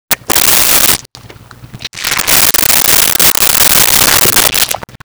Wet Squoosh Pop 06
Wet Squoosh Pop 06.wav